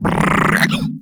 taunt1.wav